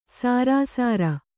♪ sārāsāra